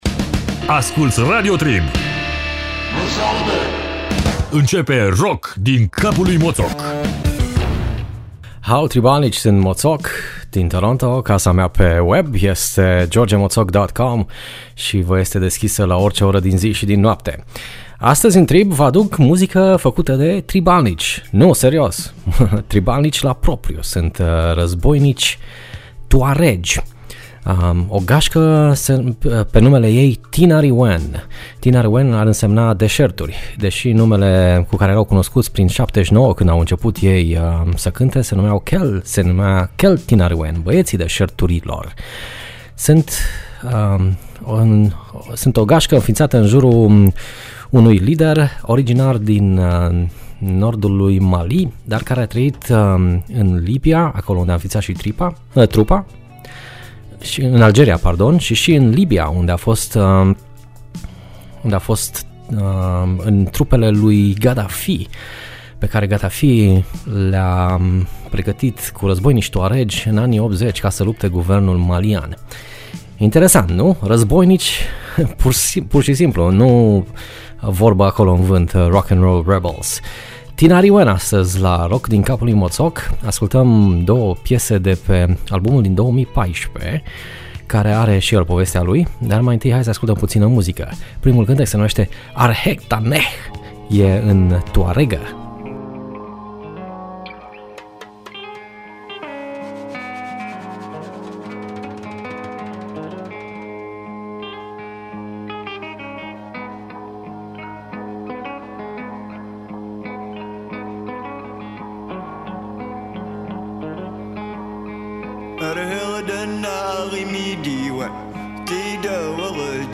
Emisiune difuzata initial la Radio Trib.